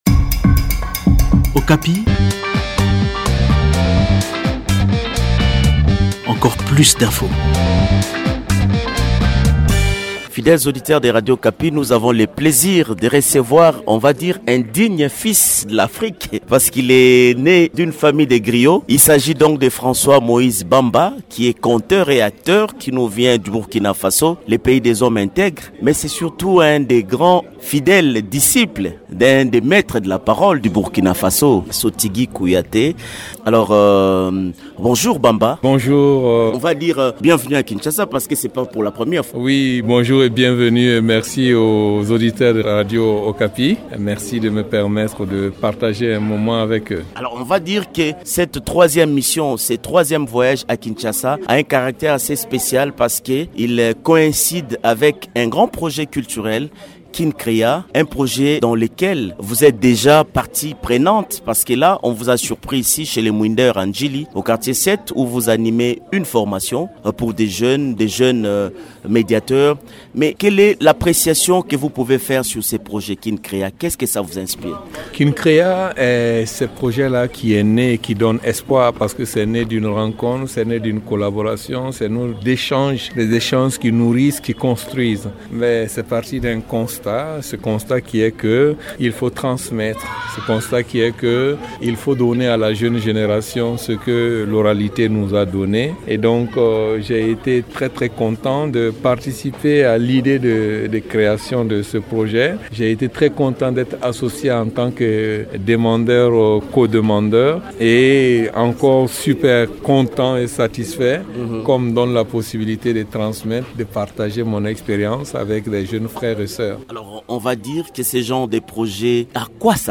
Il a l’a dit dans un entretien accordé à Radio Okapi :